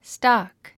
発音
stɑ’k　ストォック